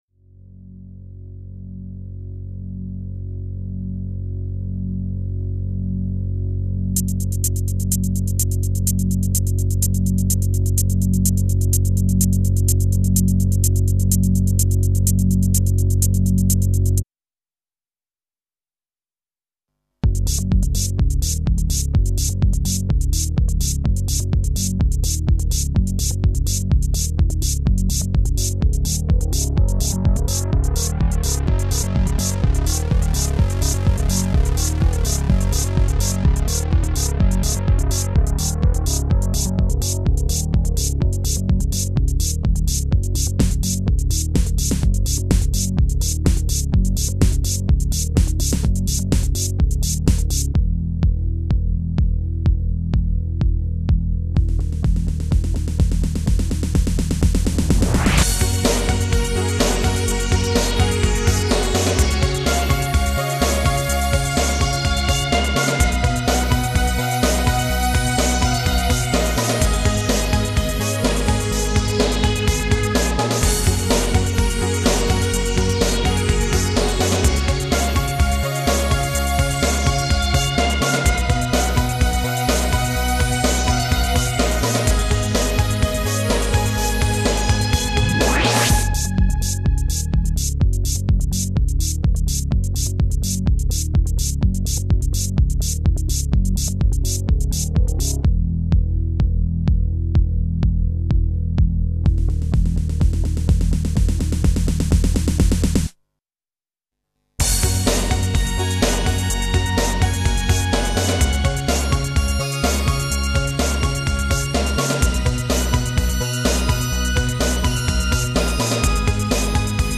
Electronique / New age
Version instrumentale d’un morceau « remix » utilisant des répliques d’un court métrage.
Roland XP 10, Roland D 110, Korg MS 20, Yamaha DX 7, Roland S 550, Roland Juno 2, Alesis MMT 8.